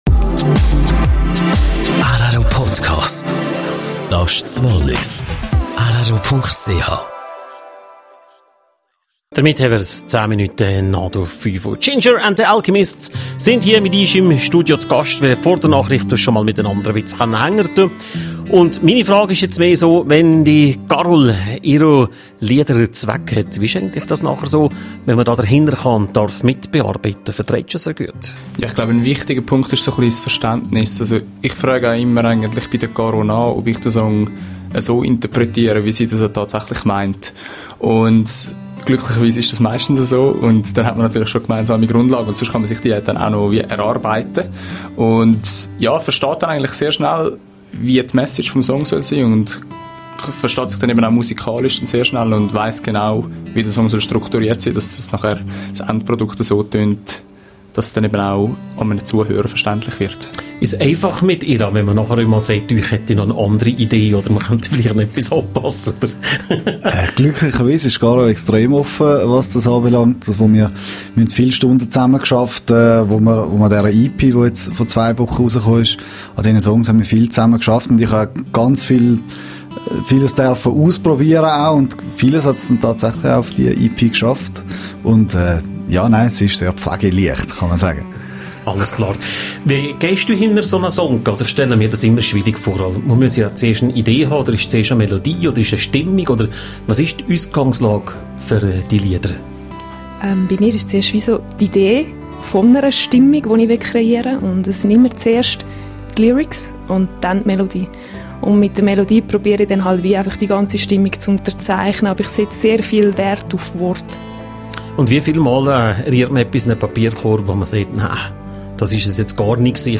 Vor ihrem Auftritt in Fiesch besuchten Ginger and the alchemists am Samstag das Radiostudio in Visp und stellten sich der rro-Community vor.